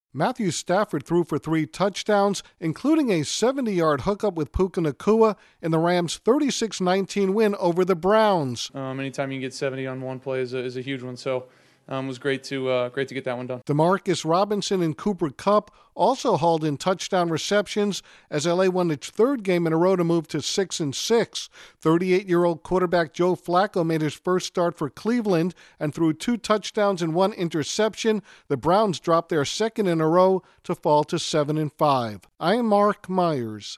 The Rams reach .500 by extending their winning streak. Correspondent